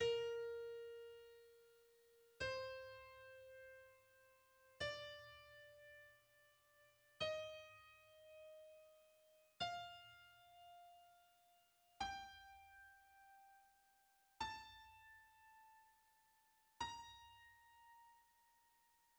Gama B-dur zawiera dźwięki: b, c, d, es, f, g, a. Tonacja B-dur zawiera dwa bemole.
Gama B-dur zapisana za pomocą
przygodnych znaków chromatycznych